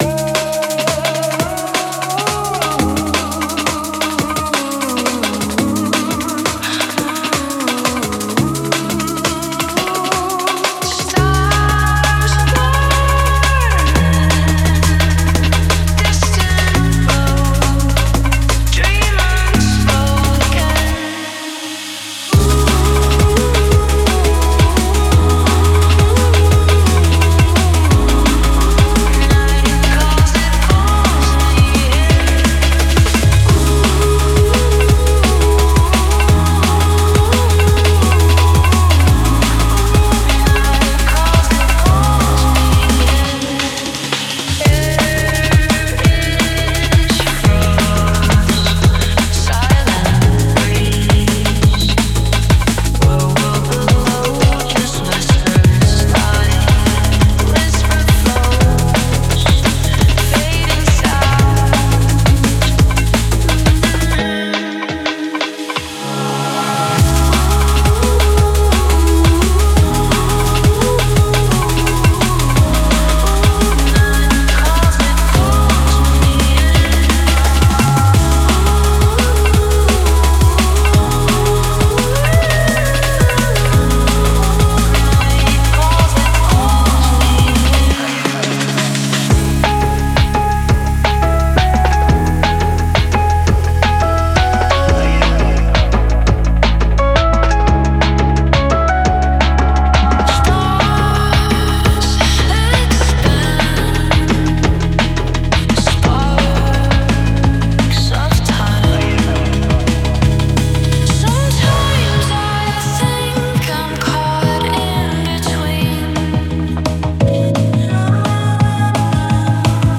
Genre Breakbeat